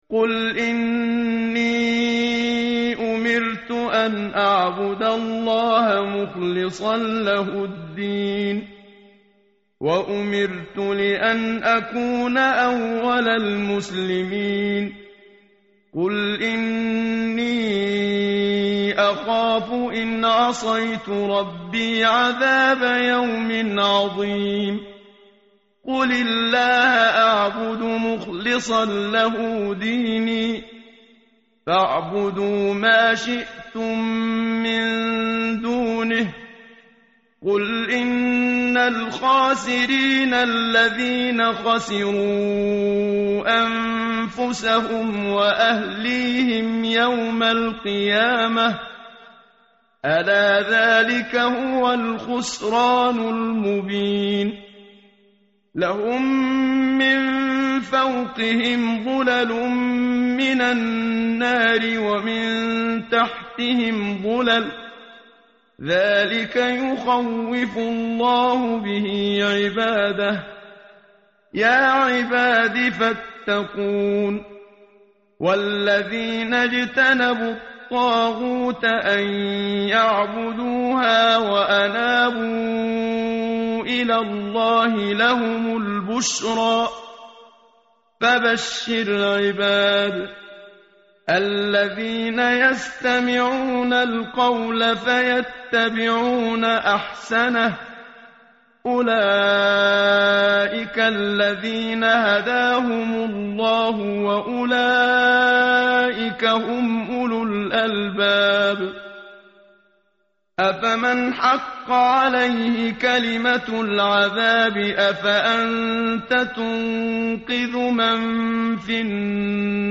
tartil_menshavi_page_460.mp3